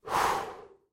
На этой странице собраны звуки горящих свечей – от тихого потрескивания до ровного горения.
Звук медленного задувания свечи человеком